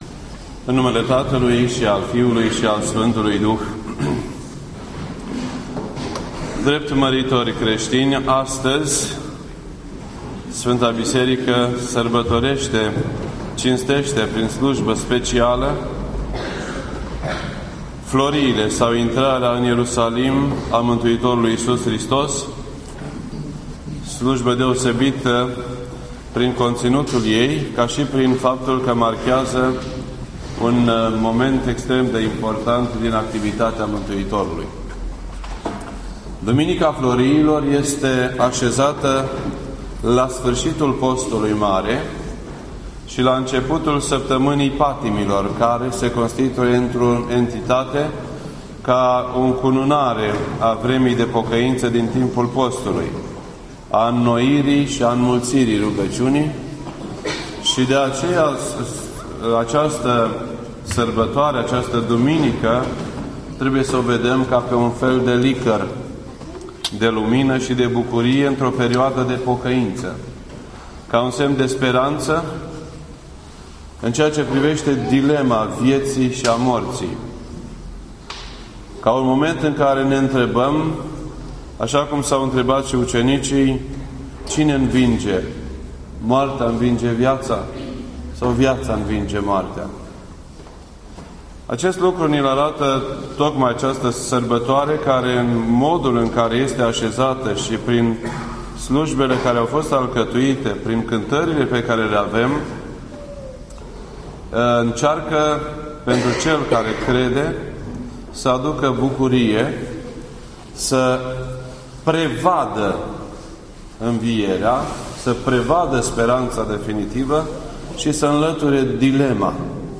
This entry was posted on Sunday, April 28th, 2013 at 8:40 PM and is filed under Predici ortodoxe in format audio.